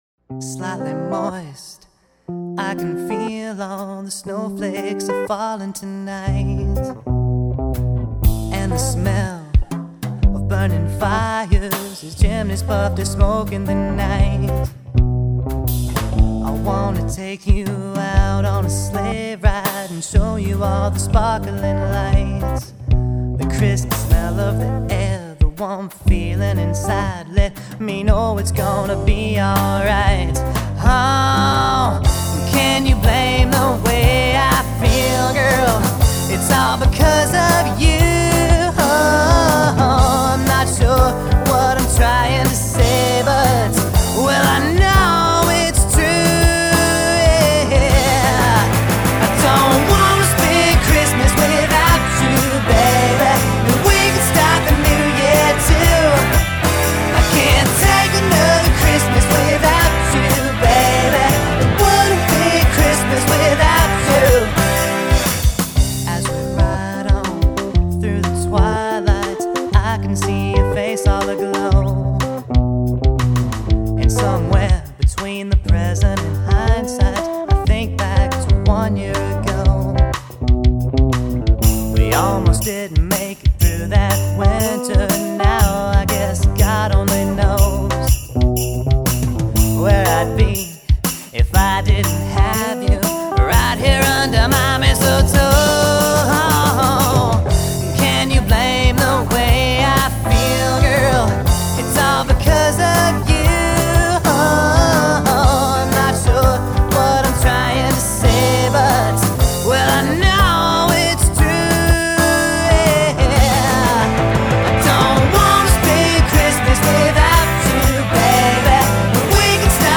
Midtempo Mlvx, Acoustic Band